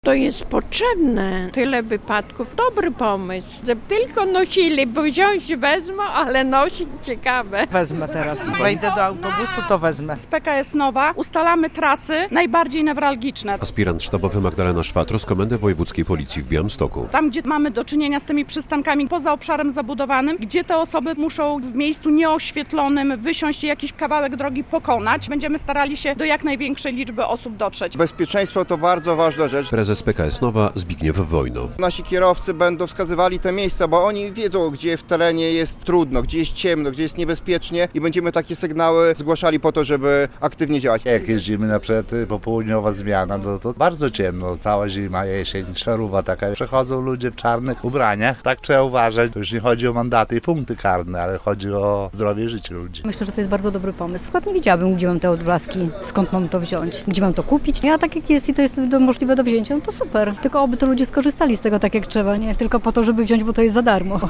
Odblaski dla pasażerów autobusów PKS Nova - relacja